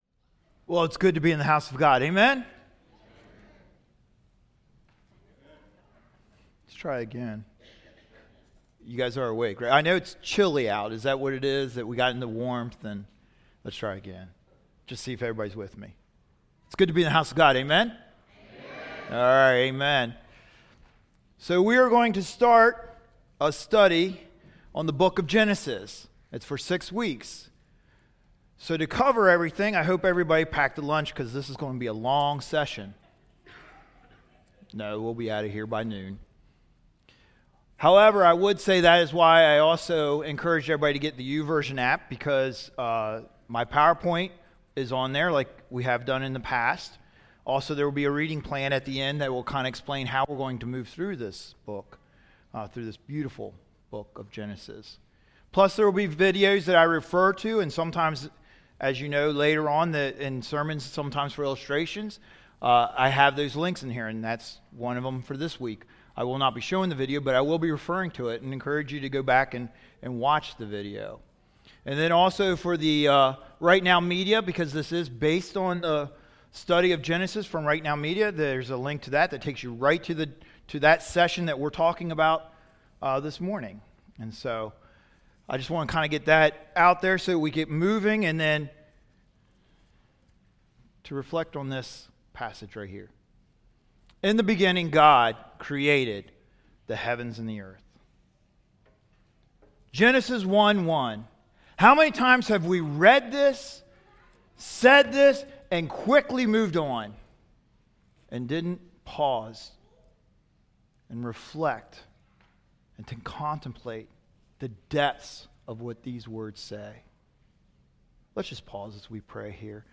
2nd Service